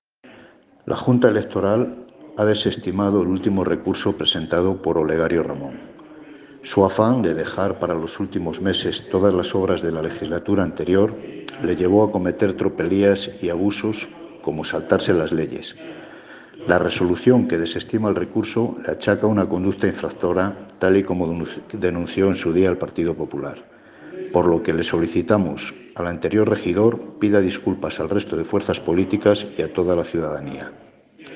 (Audionoticia): La Junta Electoral desestima el último recurso de Olegario Ramón achacándole además una conducta infractora